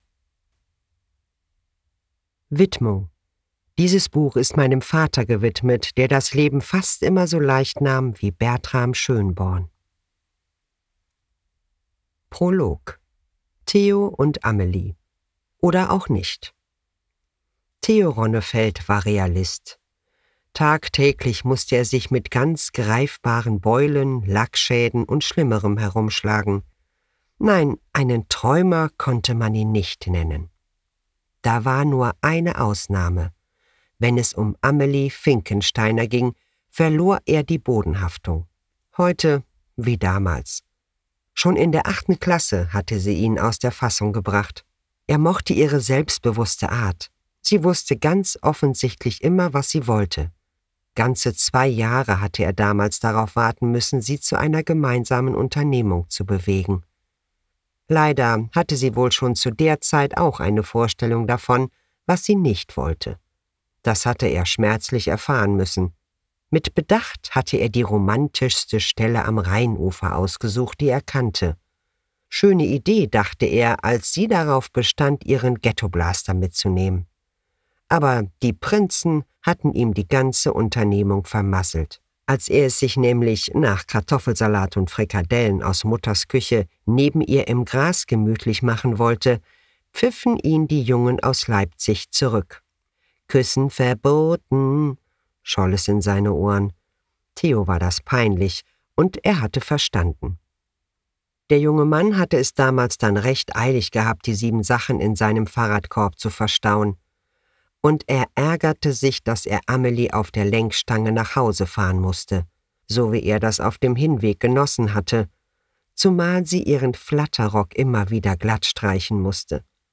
Hörbuch | Romance
Gelesen von: